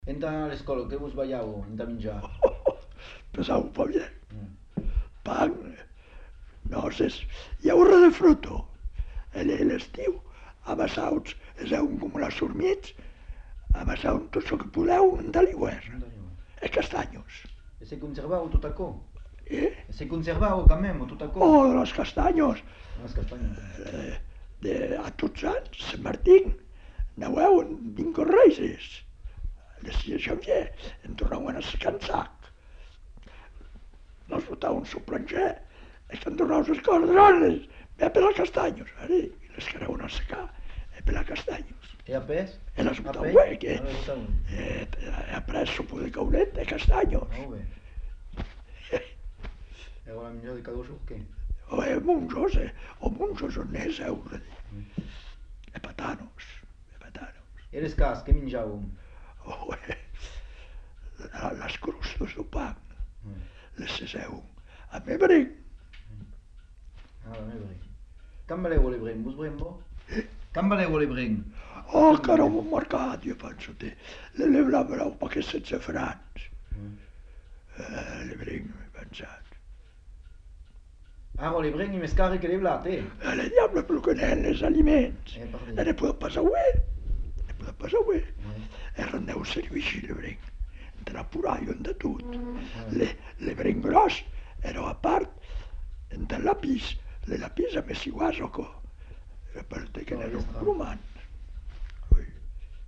Lieu : Monblanc
Genre : témoignage thématique